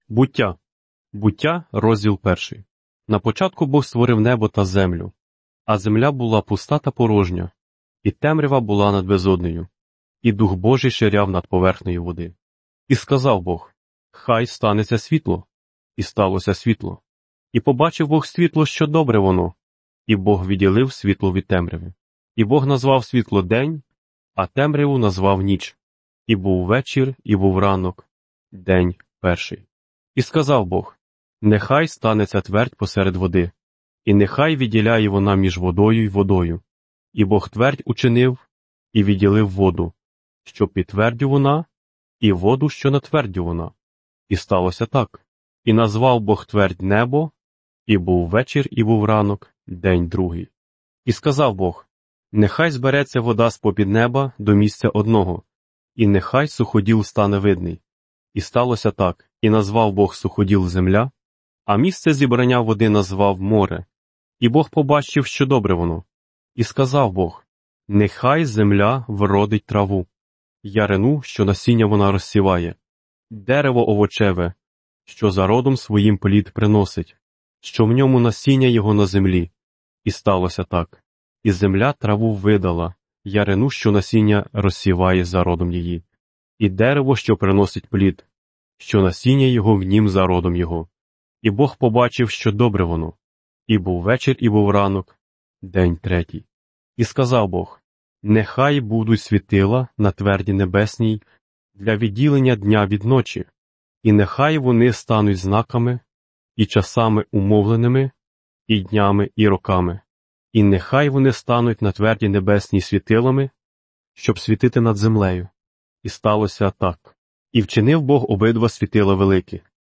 Ukrainian Audio Bible player, Bible in Ukrainian, Ukrainian Ohienko Bible 2015